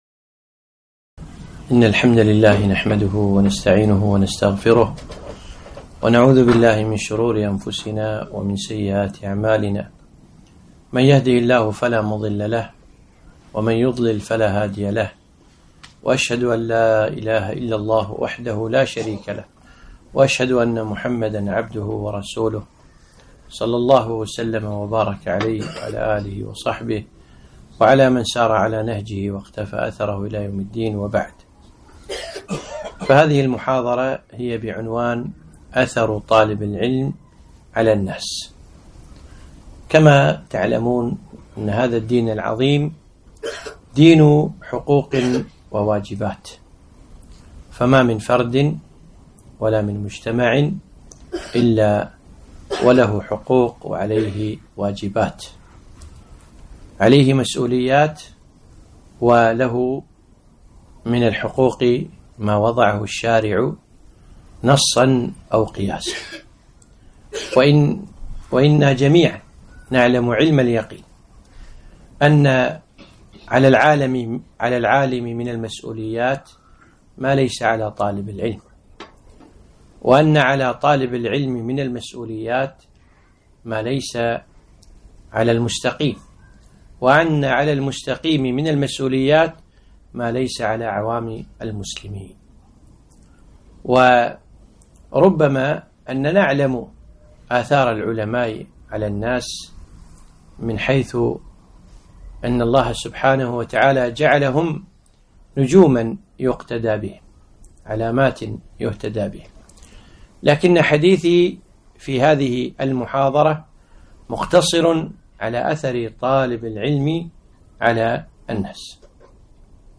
محاضرة - أثر طالب العلم على الناس